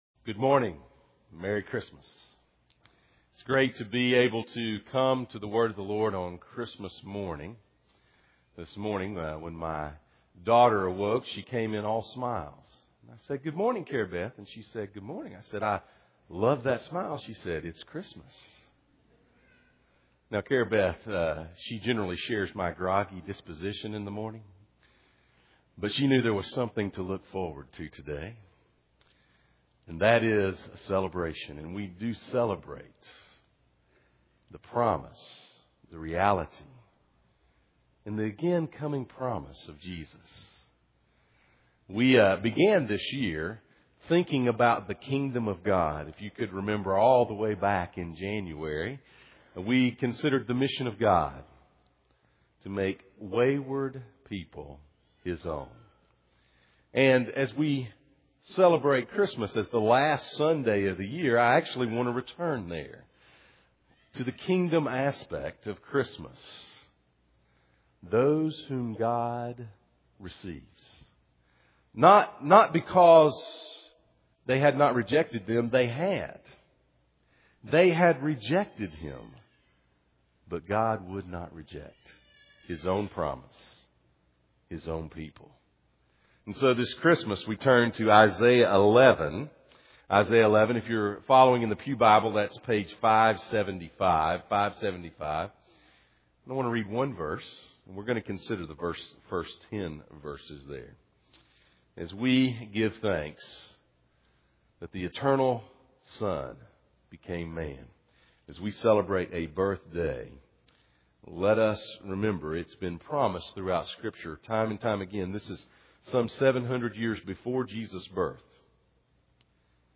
Miscellaneous Passage: Isaiah 11:1-10 Service Type: Sunday Morning « Isaiah’s Promise